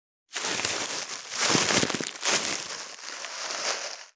665コンビニ袋,ゴミ袋,スーパーの袋,袋,買い出しの音,ゴミ出しの音,袋を運ぶ音,
効果音